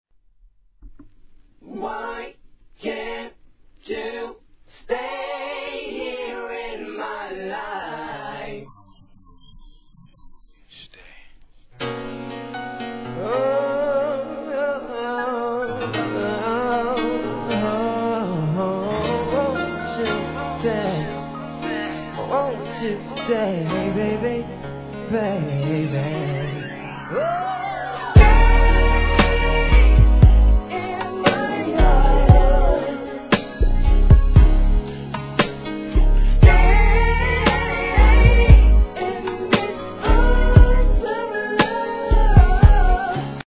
HIP HOP/R&B
スロウテンポでしっかりと歌い上げた本格派男性ヴォーカルグループ！